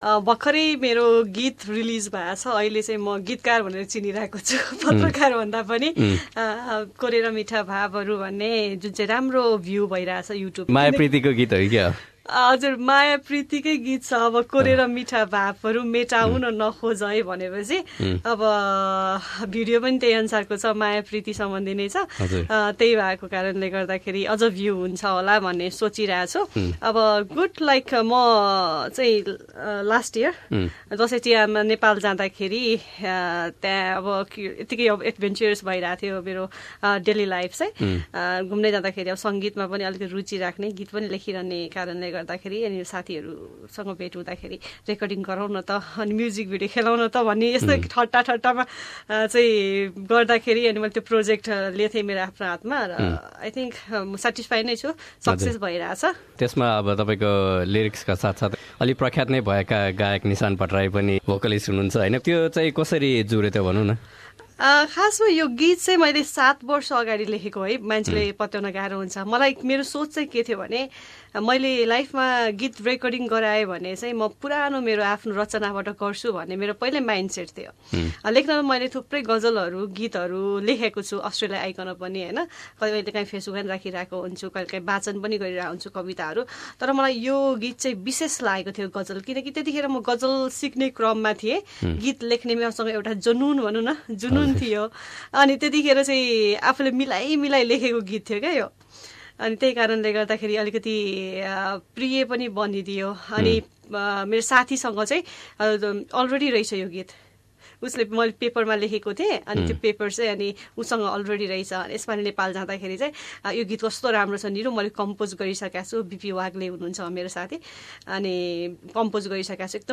कुराकानी